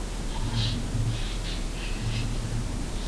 Psicofonías